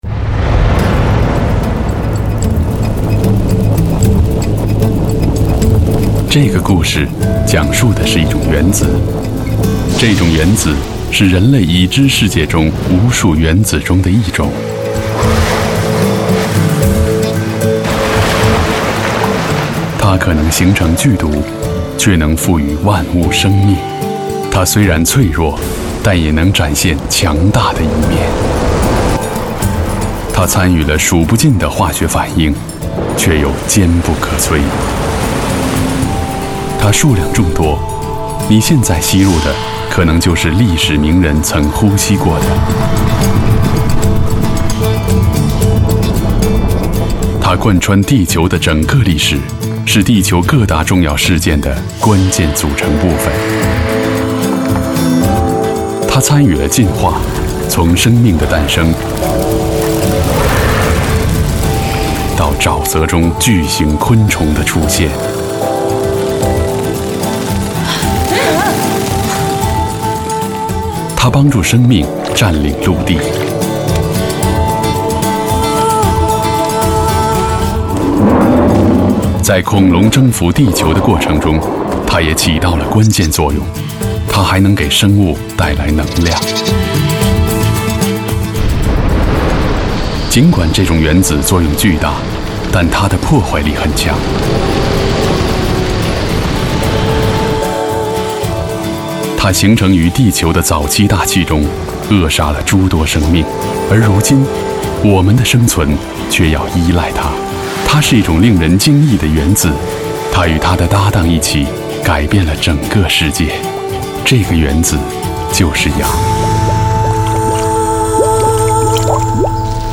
• 男11 国语 男声 纪录片—分子创造了世界 科学类 大气浑厚磁性|沉稳|娓娓道来